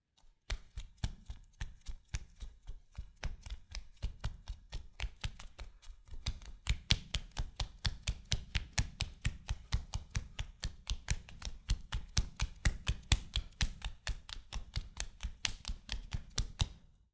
Footsteps
Concrete_Barefoot_Running.wav